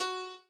b_piano1_v100l8o6fp.ogg